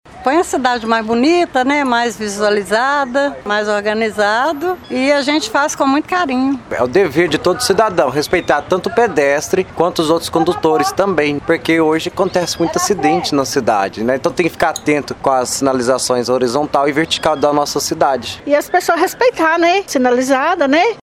O JM conversou com a equipe que falou sobre sua atuação nas ruas e a importância dos condutores ficarem mais atentos à sinalização para evitar acidentes na cidade.